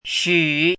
[ xǔ ]
xu3.mp3